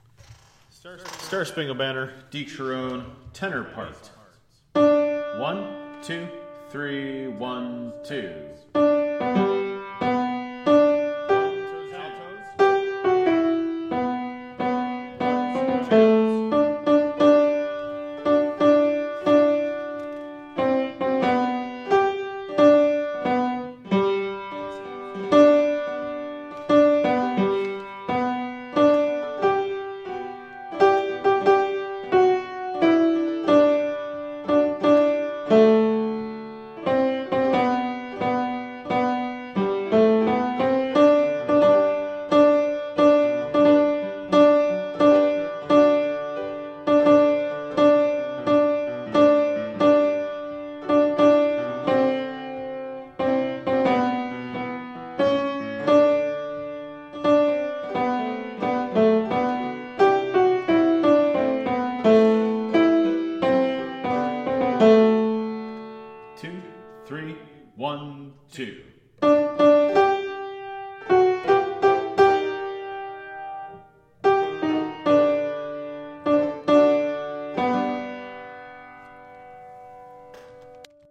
Tenor-Predom.mp3